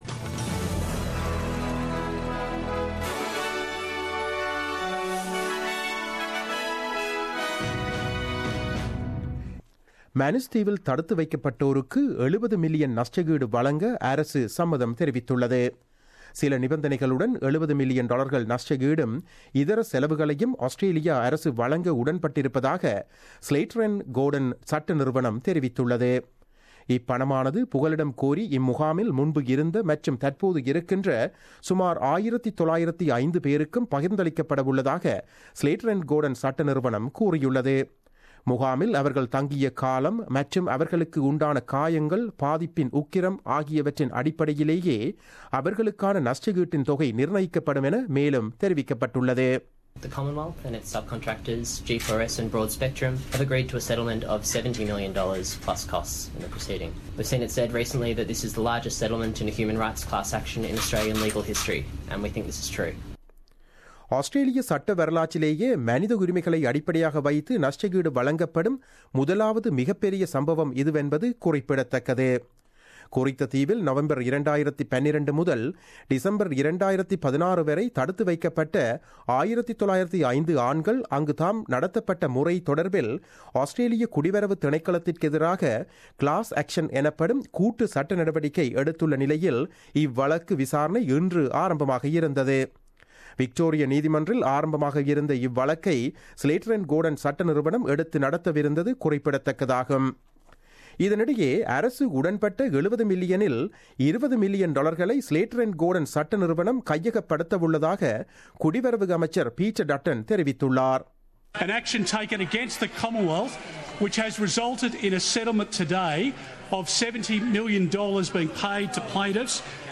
The news bulletin broadcasted on 14 June 2017 at 8pm.